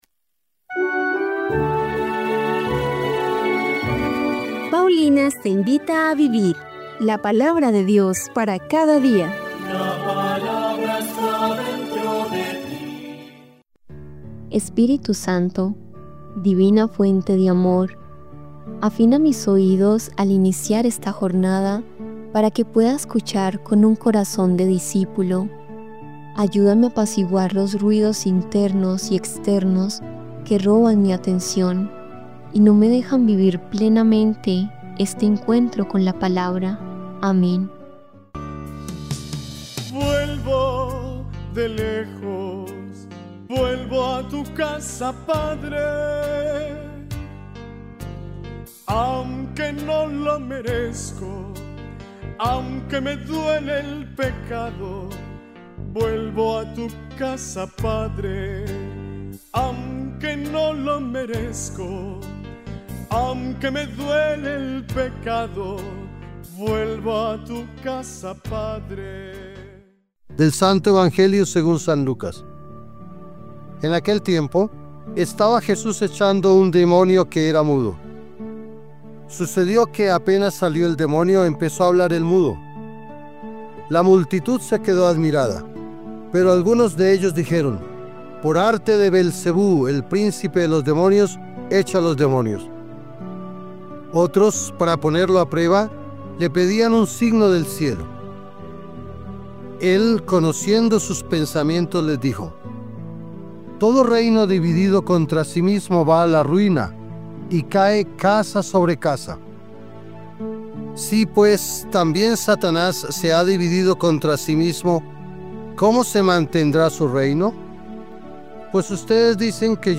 Lectura del Libro de Isaías 50, 4-9a